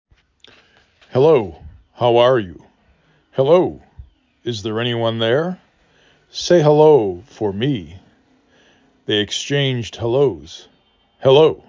5 Letters, 2 Syllable
h ə l O